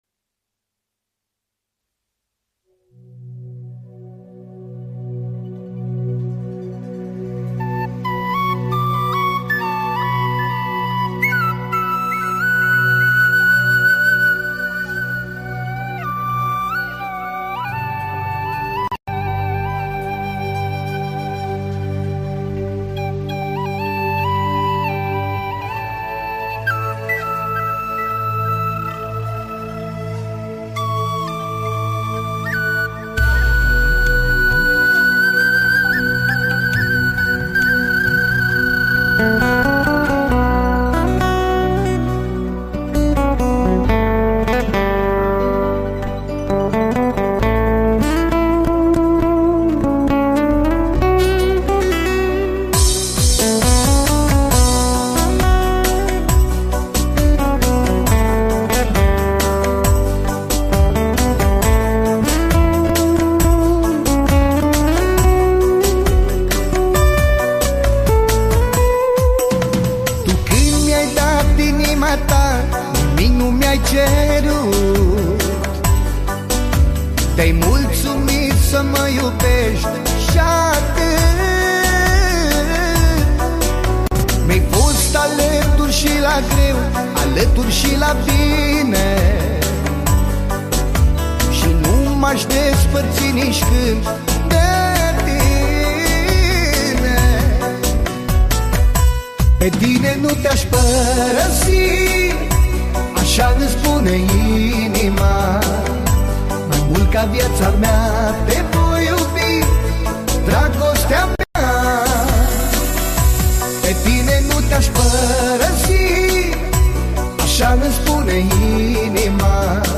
Manele Vechi